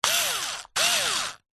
Звуки шуруповёрта, мотор
Проверка звука работы шуруповерта